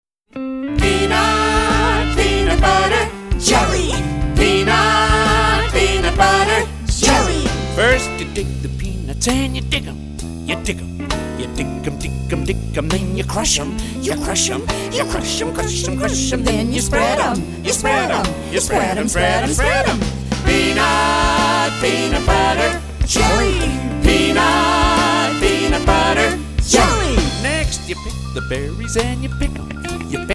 clapping rhymes, playground games and traditional camp tunes